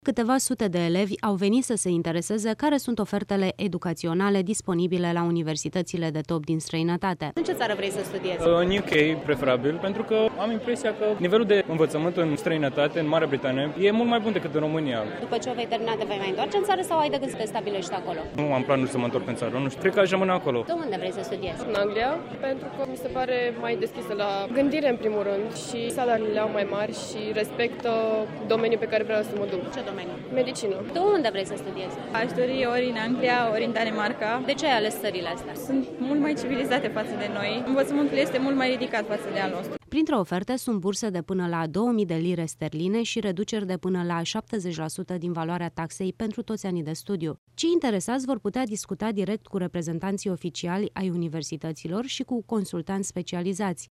Marea Britanie pare a fi alegerea multor tineri dintre cei care au ajuns , ieri, în prima zi a evenimentului la Athenee Palace Hilton din capitală.